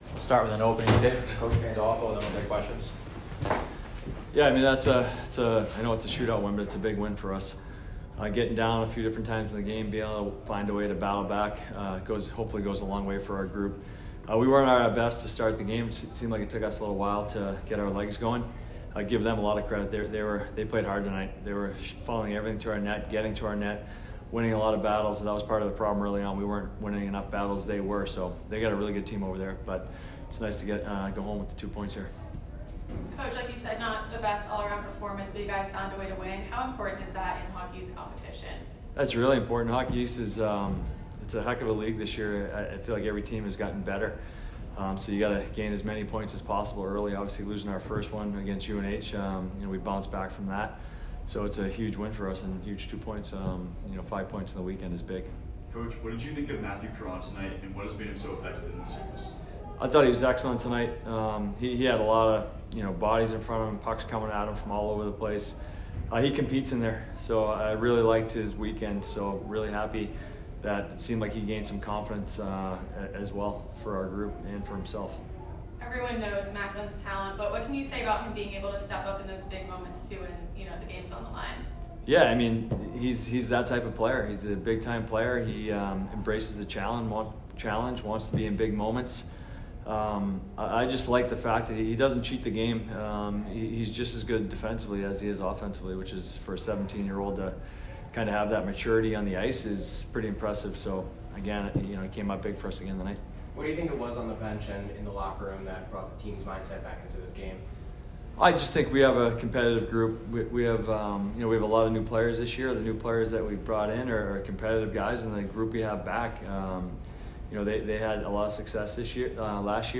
UMass Postgame Interview